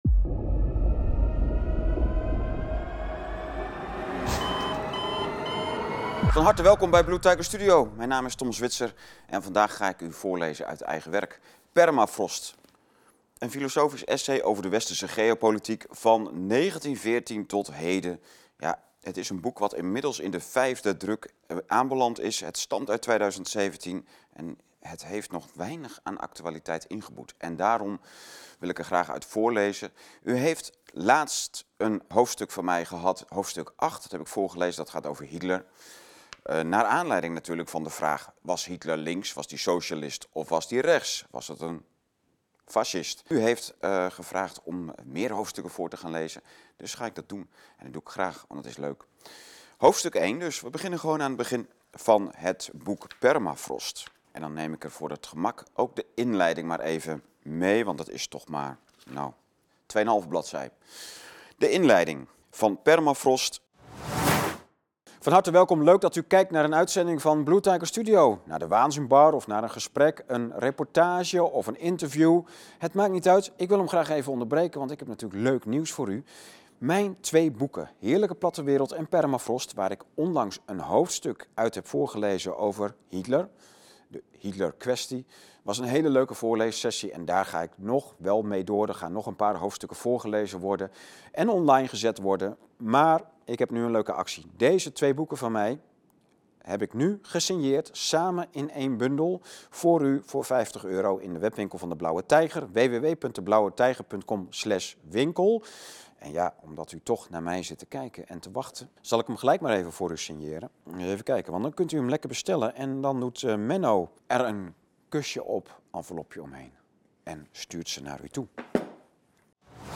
Voorlezen-Permafrost-Inleiding-hoofdstuk-1.mp3